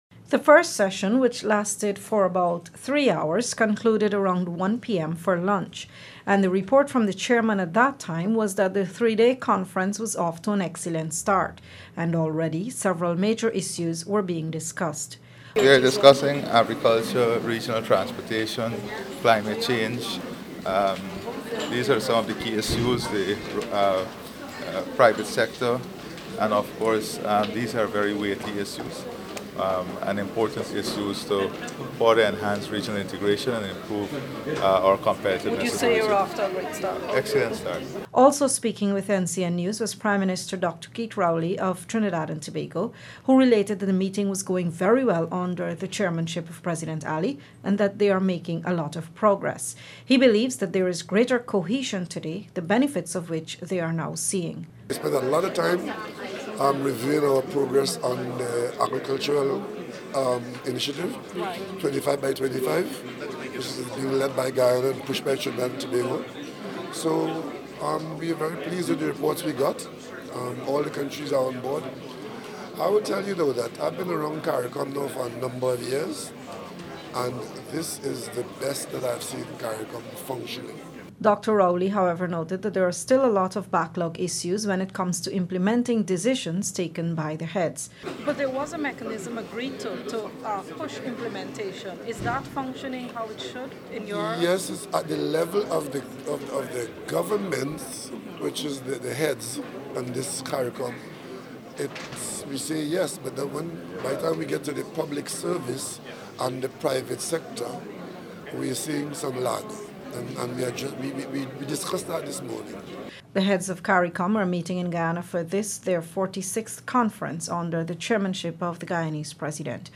Video footage from the conference captured President Ali’s remarks, where he indicated that topics such as agriculture were already being discussed, signaling a productive start to the three-day conference.